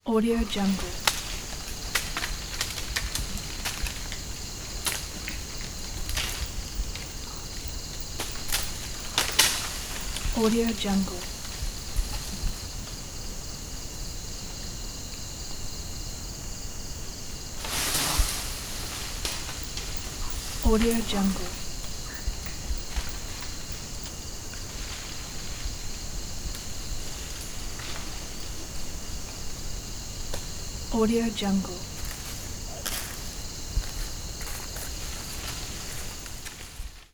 Monkey In Jungle Canopy Bouton sonore